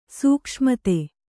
♪ sūkṣmate